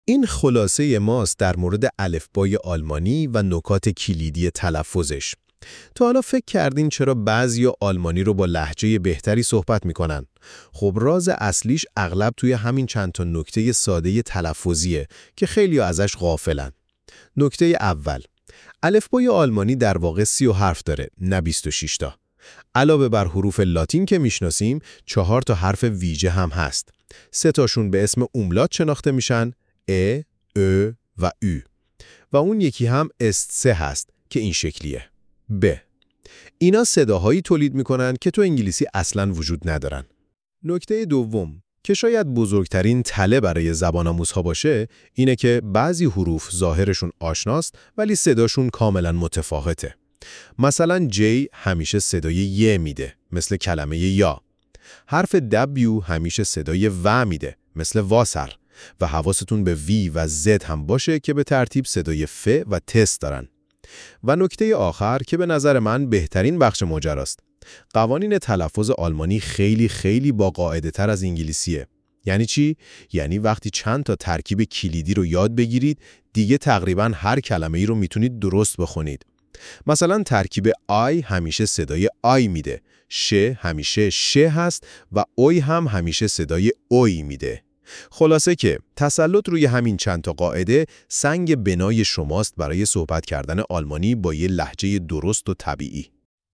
جدول کامل حروف الفبا آلمانی با تلفظ صوتی (Das deutsche Alphabet)
german-alphabet-1.mp3